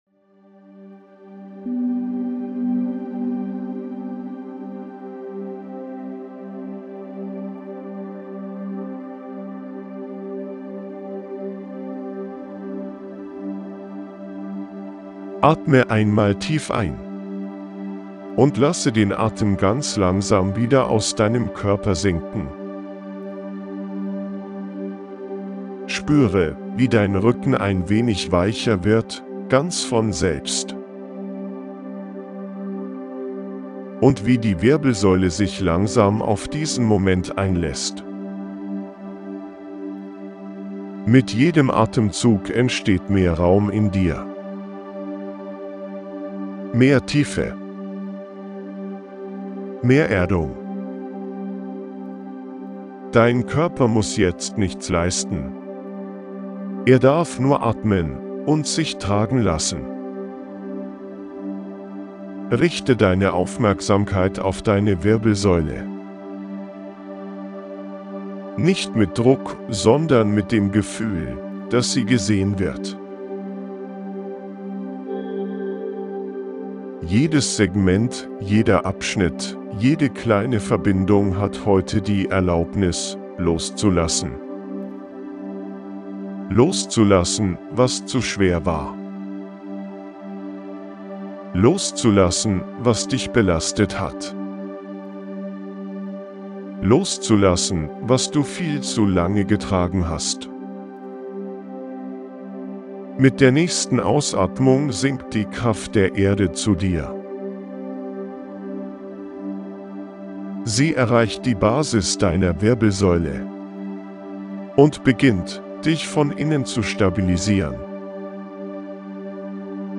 🎧 Die Audio-Meditation zum